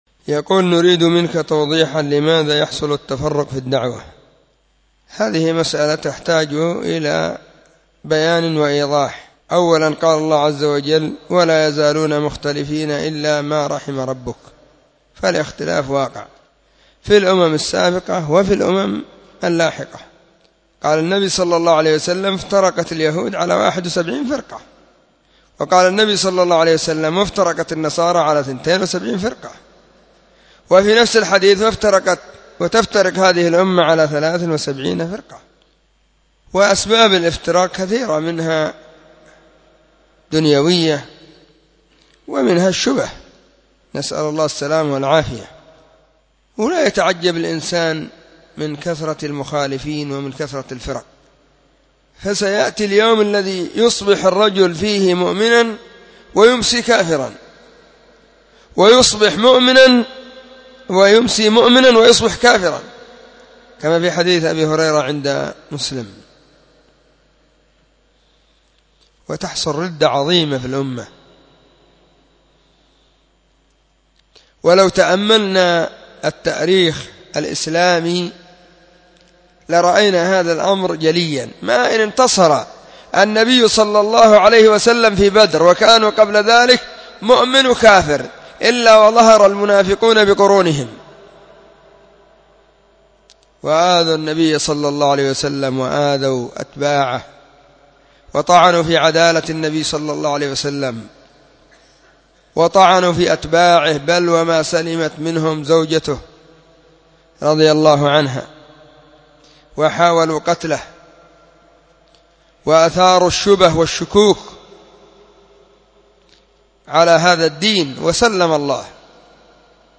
🔸🔹 سلسلة الفتاوى الصوتية المفردة 🔸🔹